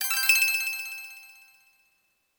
Special & Powerup (2).wav